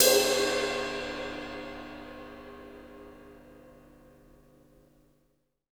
Index of /90_sSampleCDs/Roland - Rhythm Section/CYM_FX Cymbals 1/CYM_Cymbal FX
CYM BRUSH 00.wav